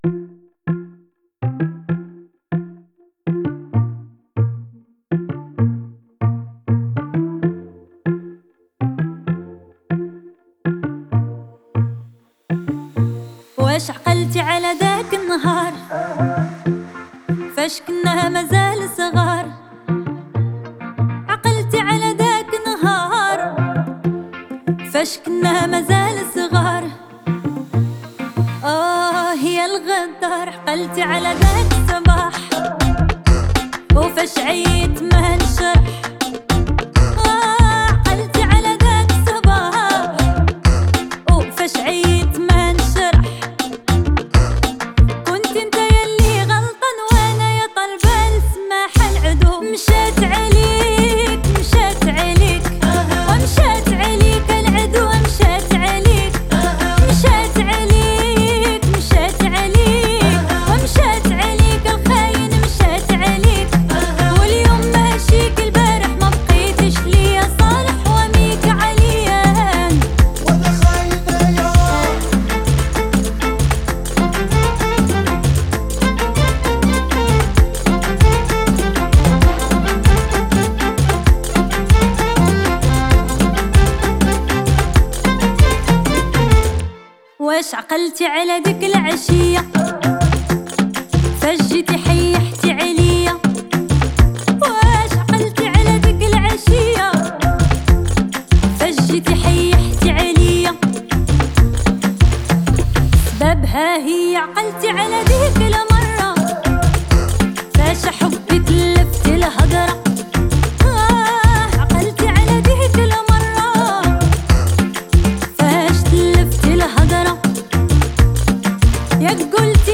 موزیک عربی جدید و زیبای